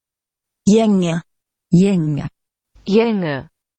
Prono guide for Samska GÄNGE ["jEN:@]